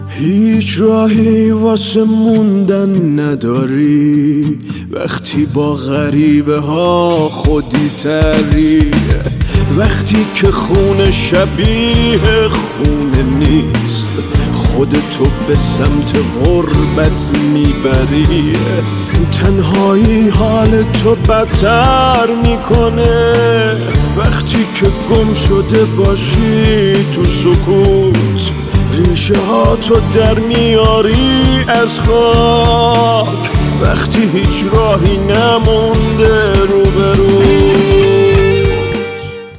زنگ خور های غمگین